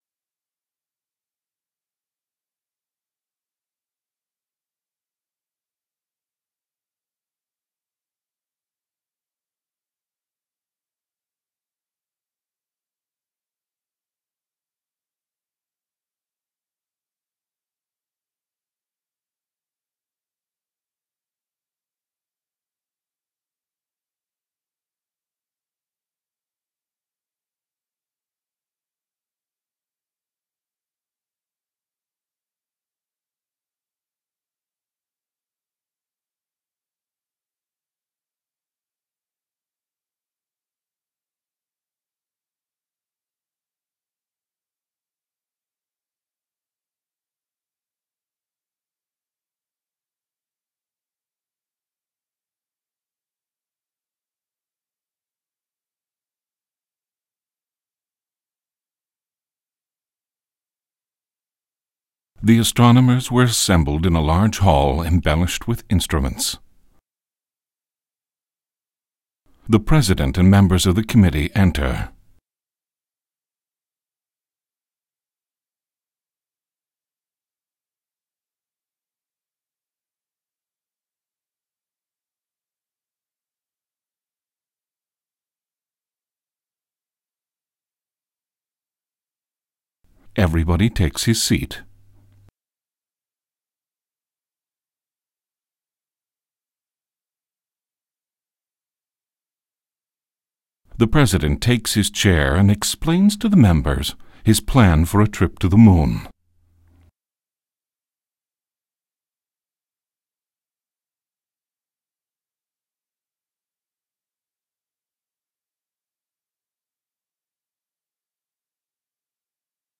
TttM Vox Stem.m4a